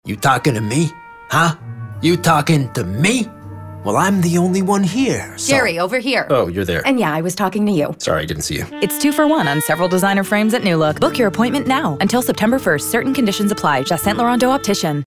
Publicité (NewLook) - ANG